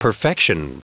Prononciation du mot perfection en anglais (fichier audio)
Prononciation du mot : perfection